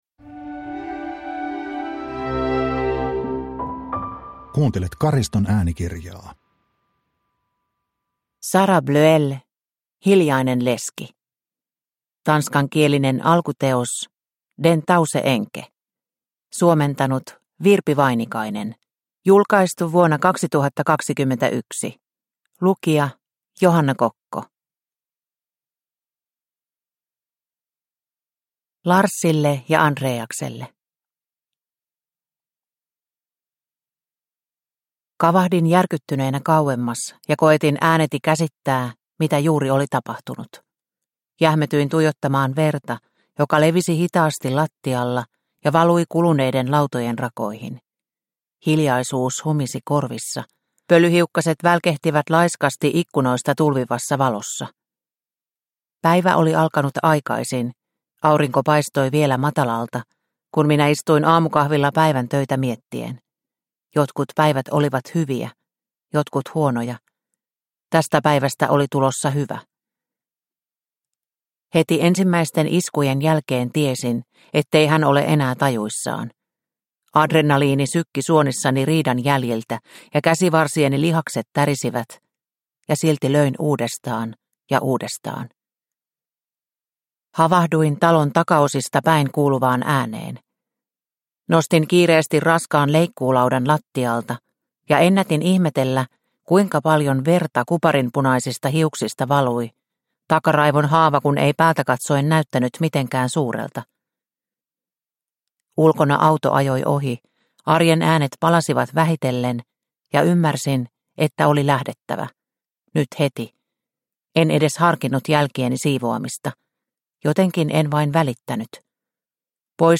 Hiljainen leski – Ljudbok – Laddas ner